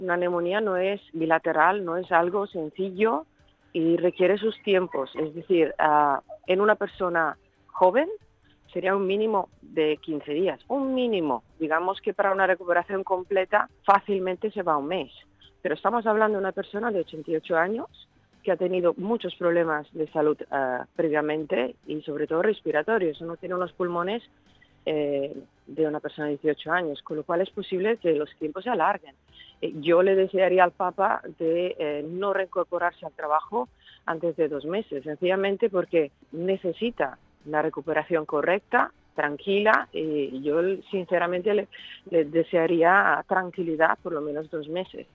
Així ho ha dit en una entrevista al programa El Matí a Ràdio Estel.